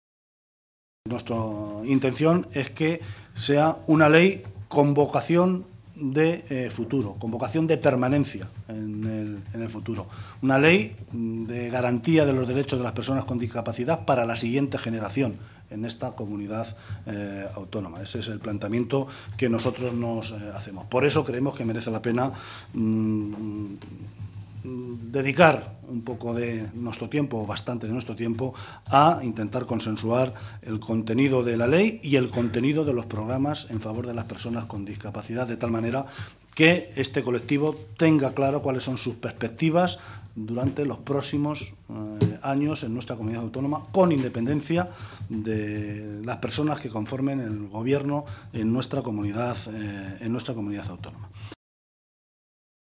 Guijarro se pronunciaba de esta manera esta mañana, en Toledo, en una comparecencia ante los medios de comunicación en la que avanzaba las intenciones de los socialistas en el contexto de la tramitación parlamentaria de la Ley de de Garantías y Derechos de las personas con discapacidad.